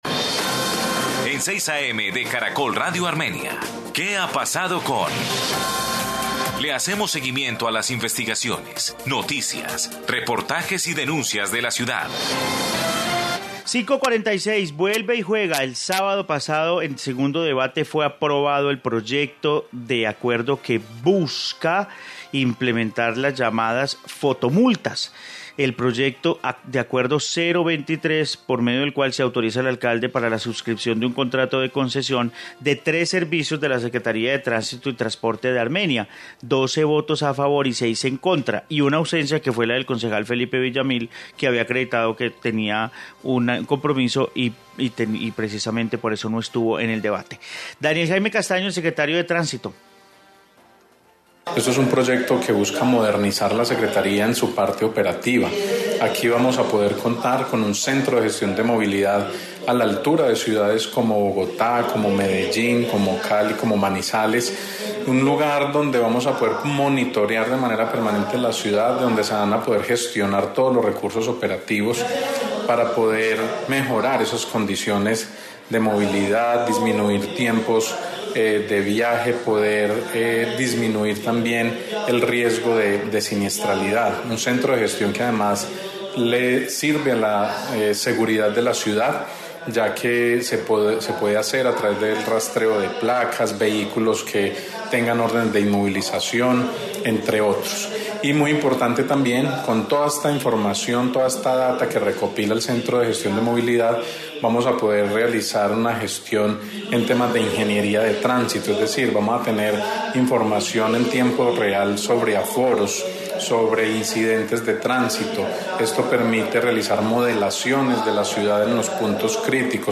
Informe fotomultas en Armenia